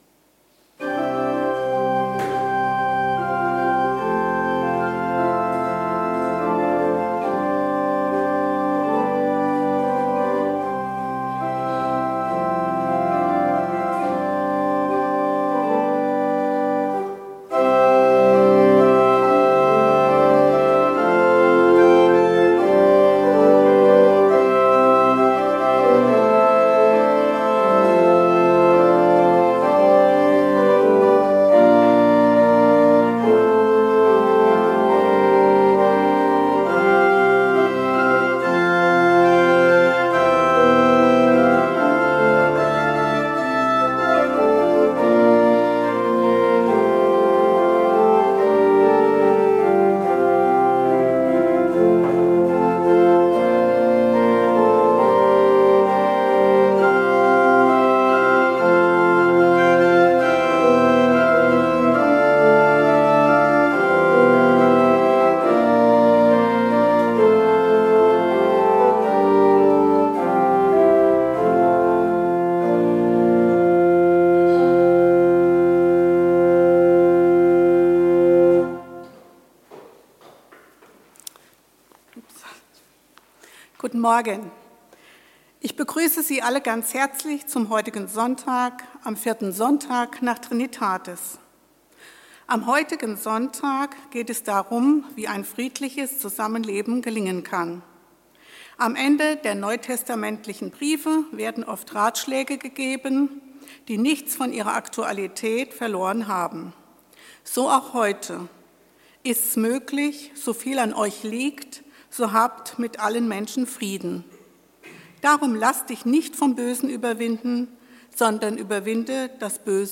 Gottesdienst vom Sonntag, den 23.
aus der evangelischen Kirche Naunheim